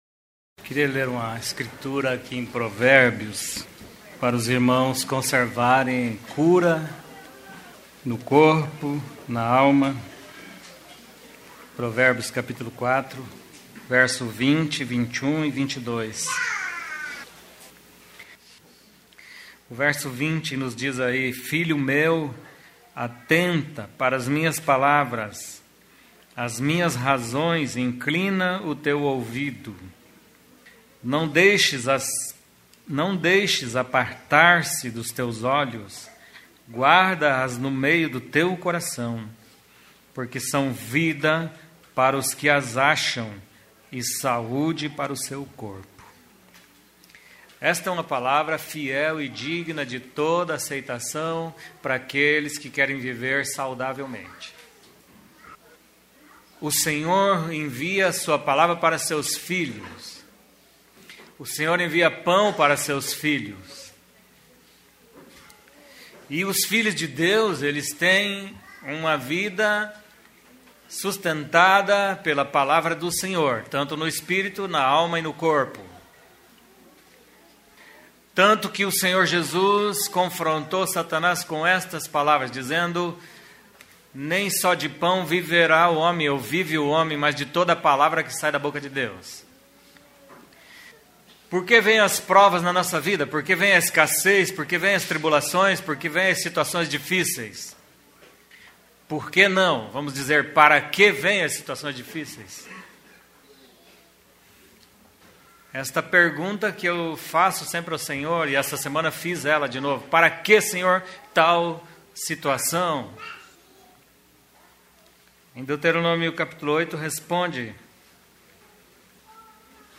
Mensagem compartilhada na reunião da igreja em Curitiba no dia 14/06/14.